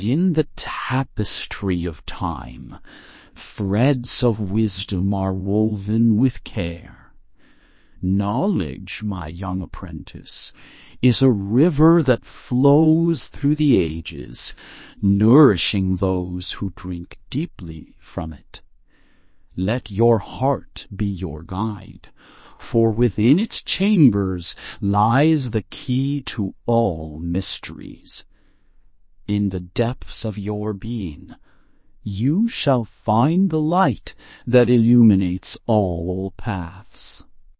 Example 1: Character Voices
Wise elderly wizard with a slightly raspy voice, speaking slowly and deliberately.